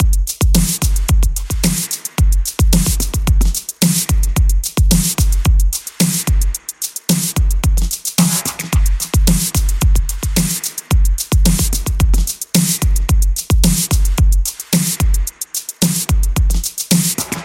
描述：调：Fmin 节奏：110bpm 有点像Breakbeat和80年代的坏电影，现在喜欢这种80年代的氛围。